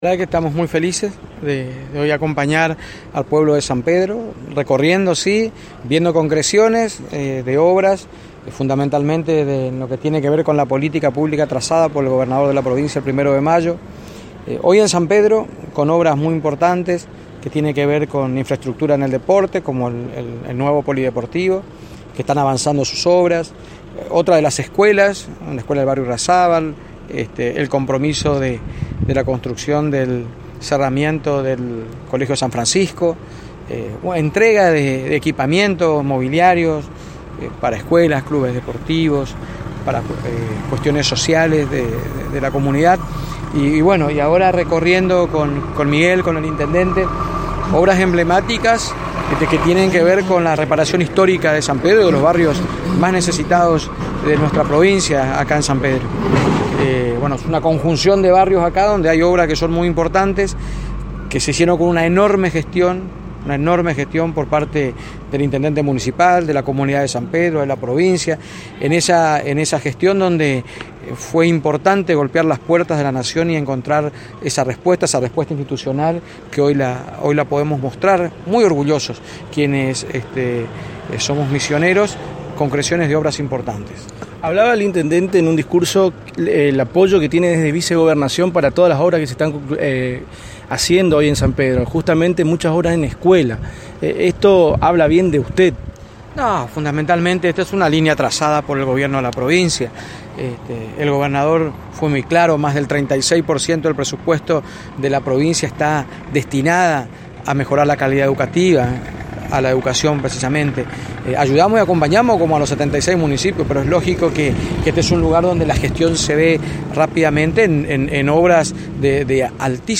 entrevista-a-herrera.mp3